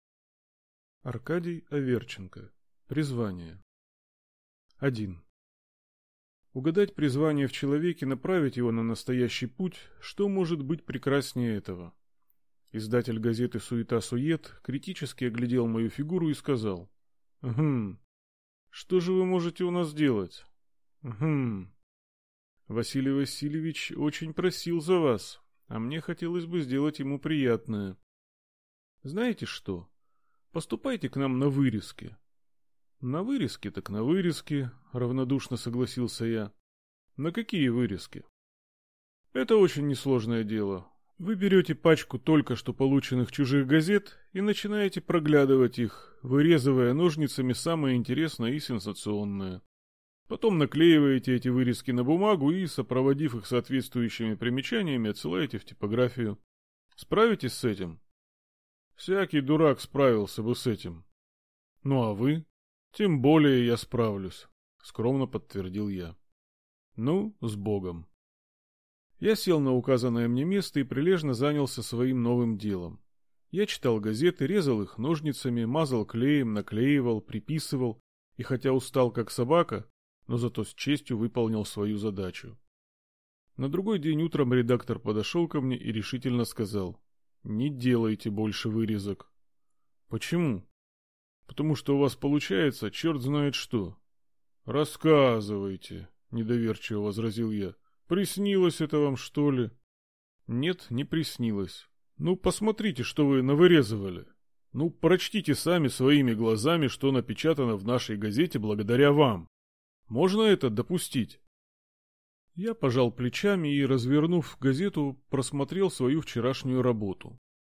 Aудиокнига Призвание